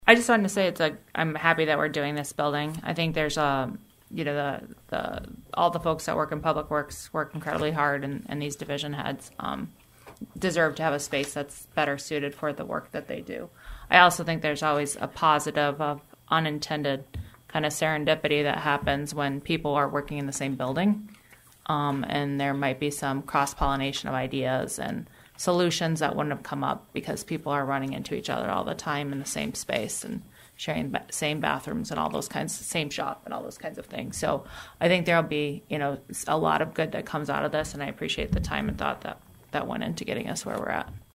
ABERDEEN, S.D.(HubCityRadio)- On Monday night, the Aberdeen City Council discussed an ordinance dealing with funding needed for the new Public Work facility.
Councilwoman Erin Fouberg praised the work done to get to this point.